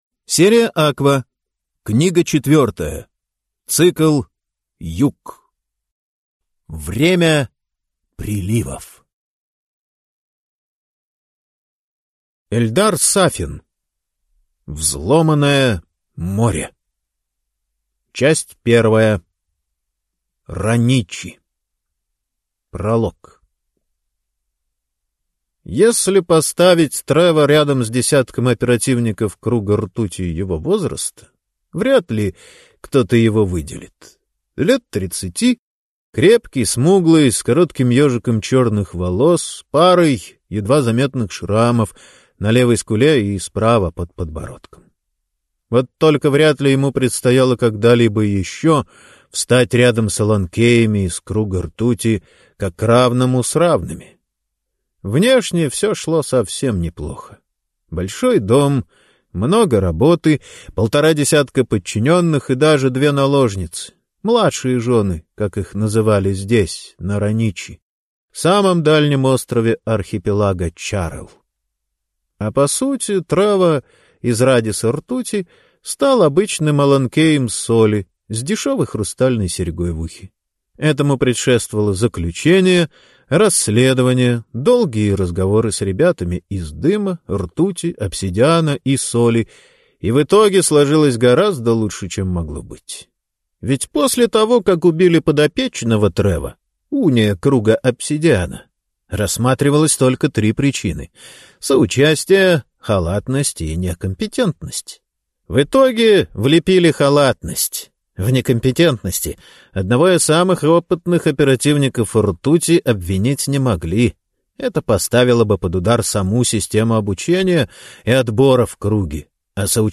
Аудиокнига Аква 4. Время приливов | Библиотека аудиокниг